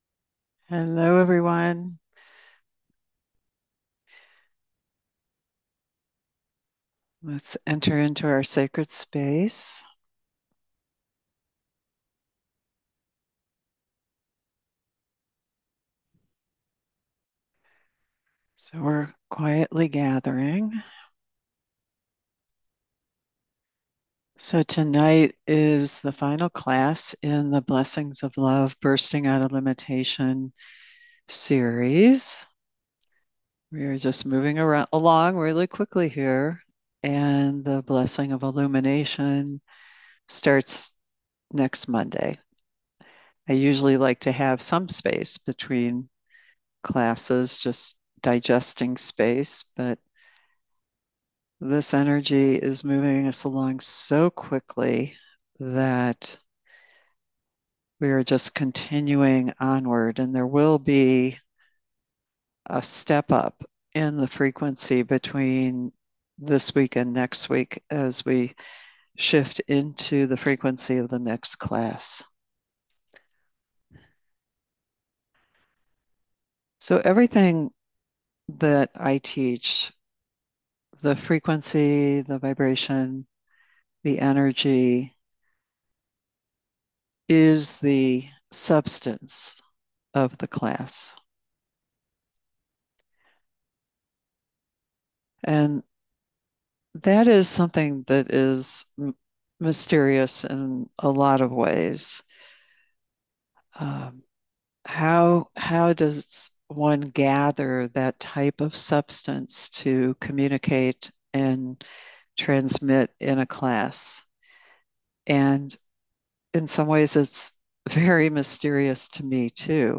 Genre: Guided Meditation.